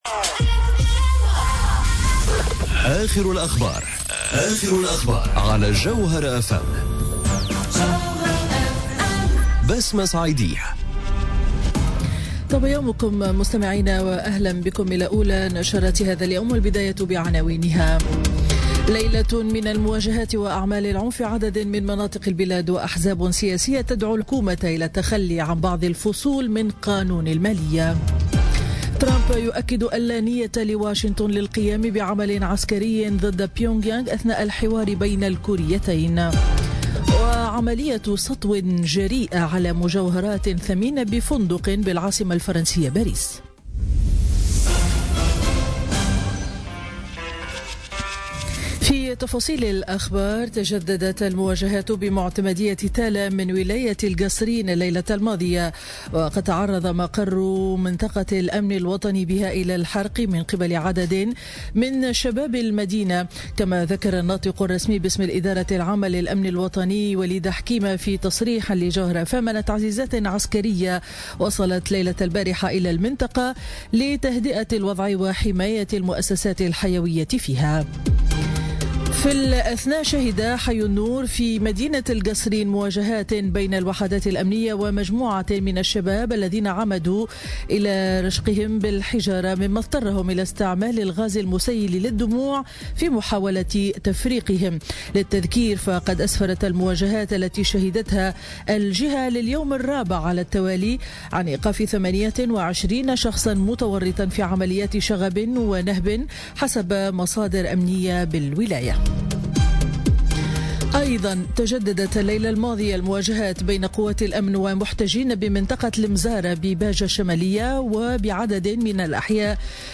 نشرة أخبار السابعة صباحا ليوم الخميس 11 جانفي 2017